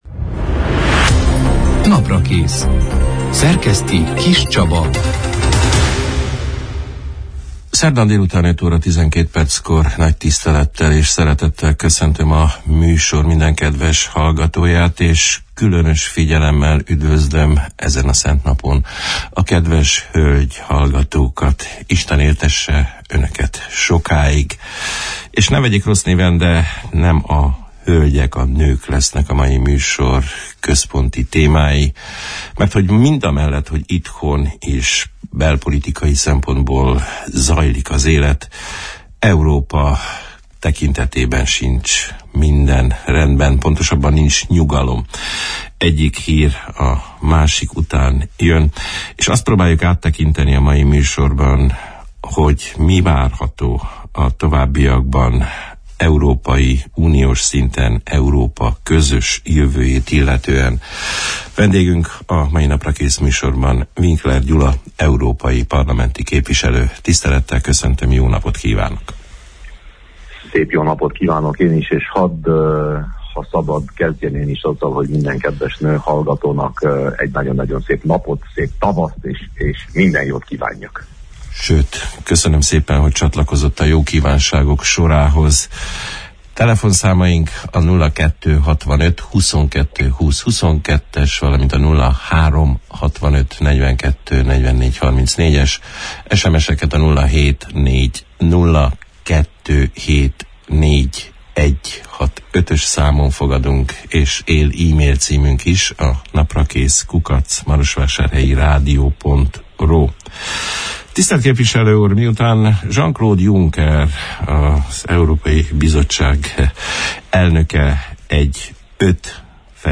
A többsebességes Európa beindításának esélyeiről, az új Dublini rendelet javaslatának beterjesztéséről az Európai Parlamentben, a humanitárius vízum odaítélésének kritériumairól, Kelemen Hunor szövetségi elnök brüsszeli látogatásának konkrétumairól, a közösségi programok idei, Hunyad megyei előtérbe helyezéséről beszélgettünk a március 8 -án, szerdán elhangzott Naprakész műsorban Winkler Gyula európai parlamenti képviselővel.